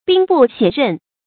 注音：ㄅㄧㄥ ㄅㄨˋ ㄒㄩㄝˋ ㄖㄣˋ
兵不血刃的讀法